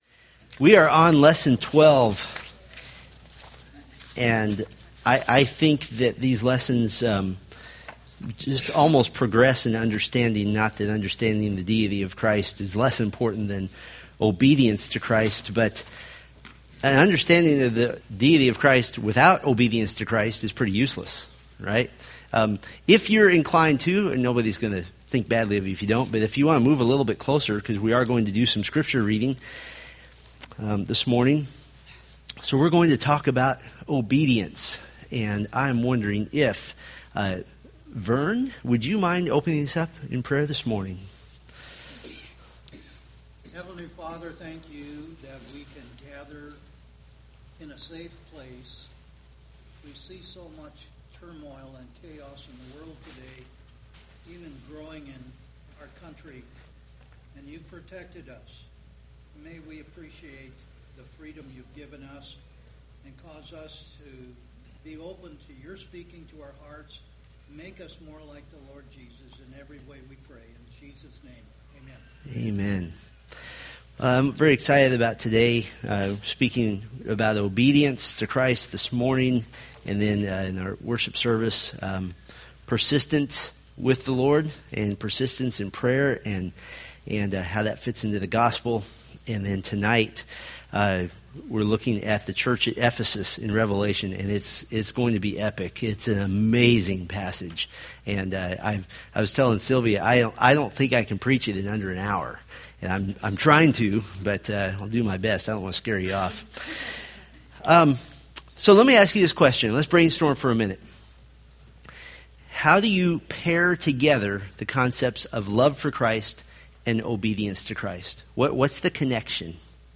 Date: Sep 29, 2013 Series: Fundamentals of the Faith Grouping: Sunday School (Adult) More: Download MP3